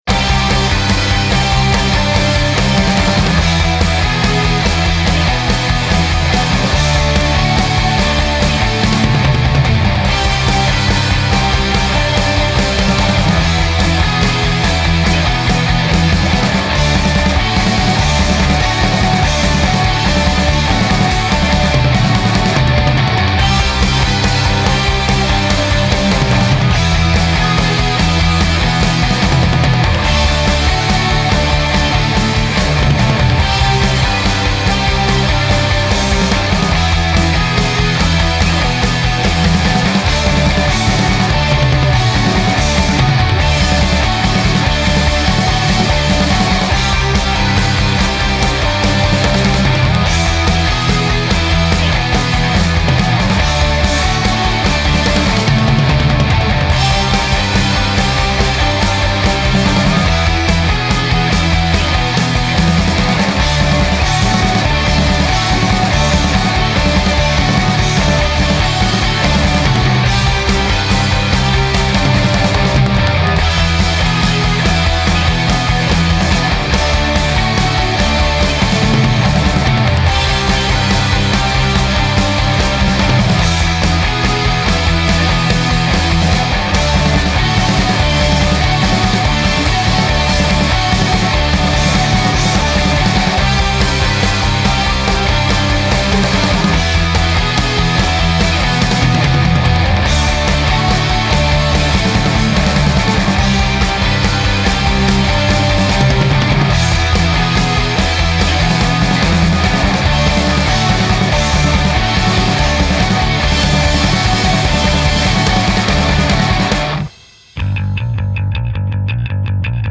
un démarrage porté par des guitares presque pop